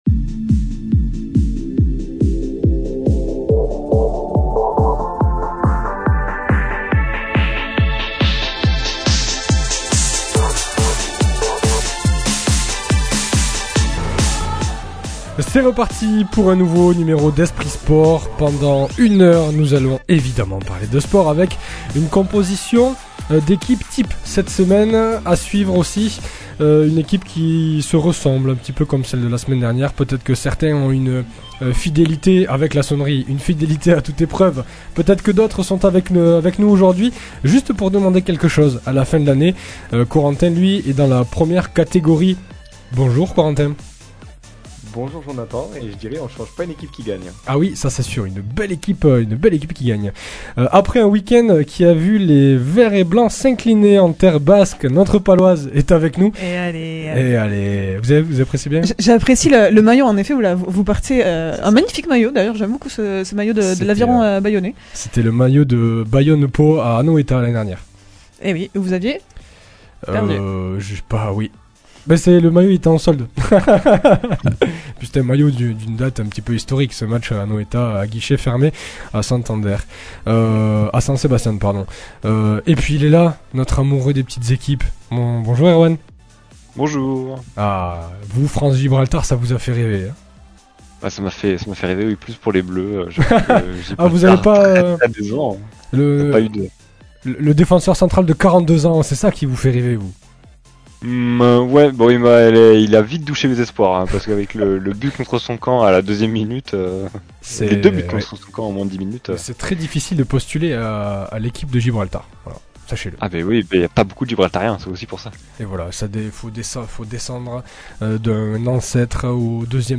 Pour débuter cette émission, l'équipe titulaire du jour nous donne ses Top/Flop du week-end, suivi d'un entretien